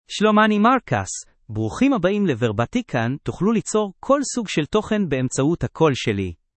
MarcusMale Hebrew AI voice
Marcus is a male AI voice for Hebrew (Israel).
Voice sample
Male
Marcus delivers clear pronunciation with authentic Israel Hebrew intonation, making your content sound professionally produced.